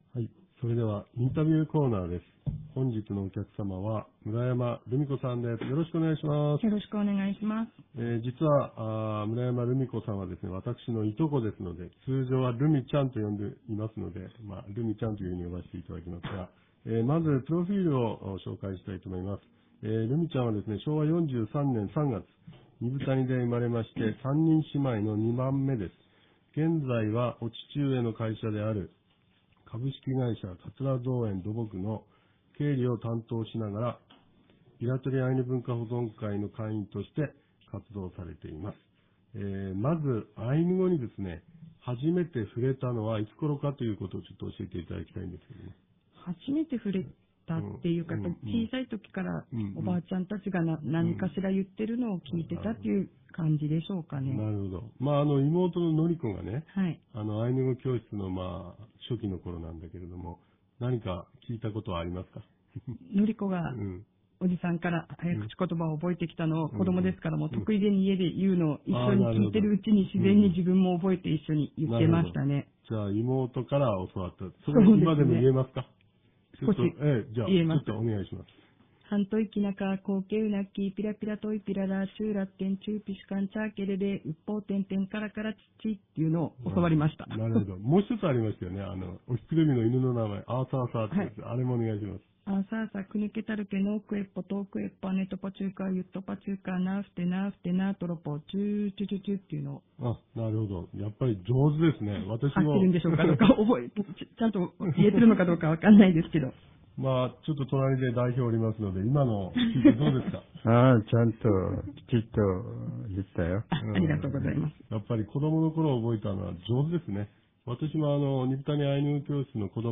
■インタビューコーナー